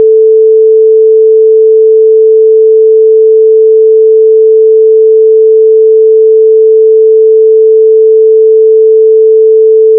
24/44.1 stereo 10 second 440 hertz wave file